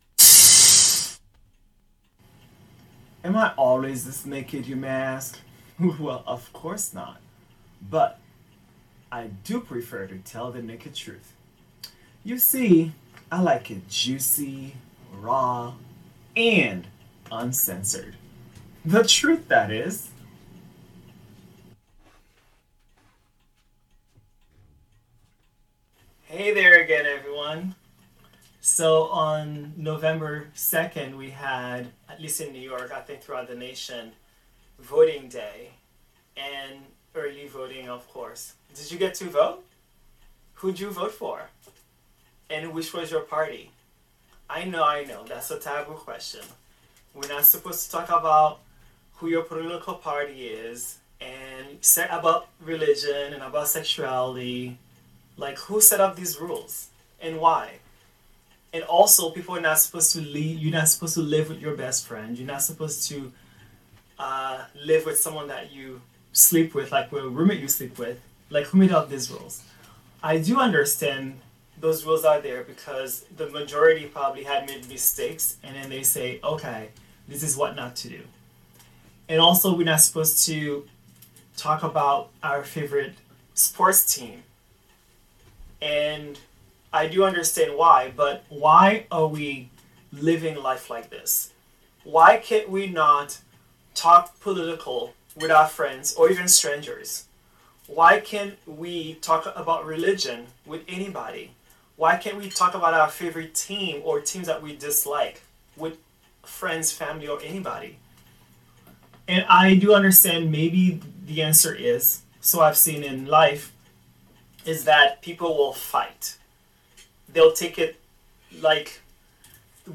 Solo.